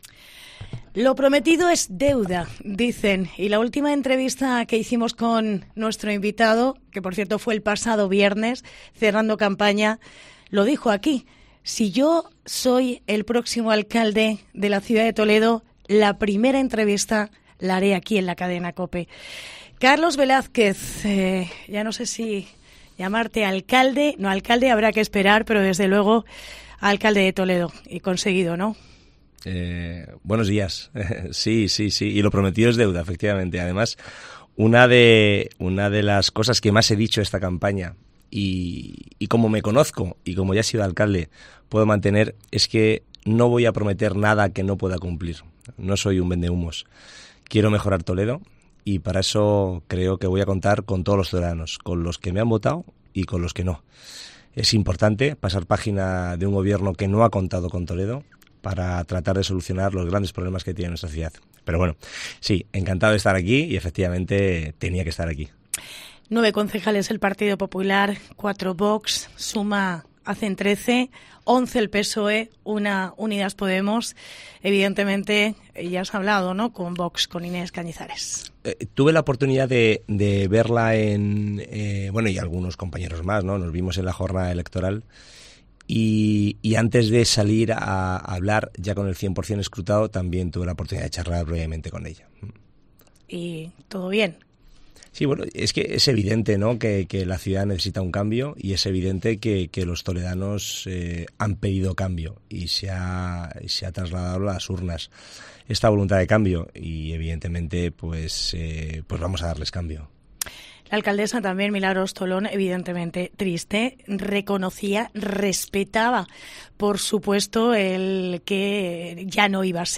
Entrevista en COPE Toledo a Carlos Velázquez tras el 28M